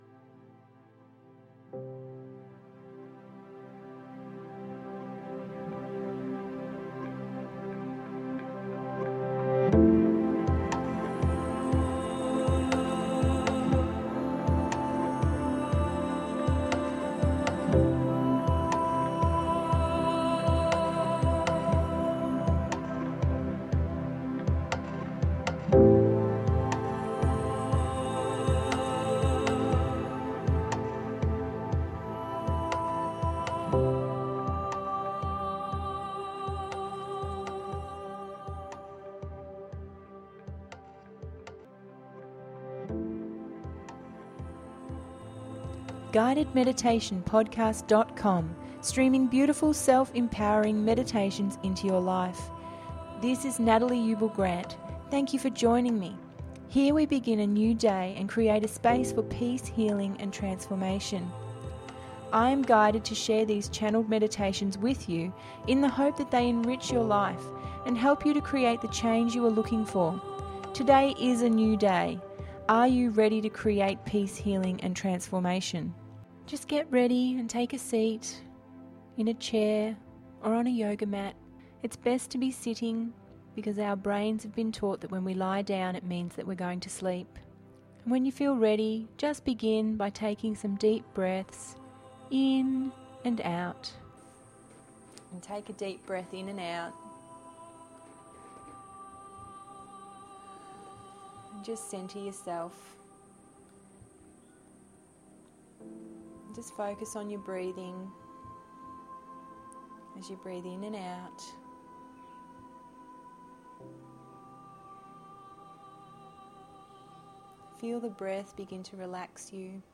Connect with the sacred feminine…051 – GUIDED MEDITATION PODCAST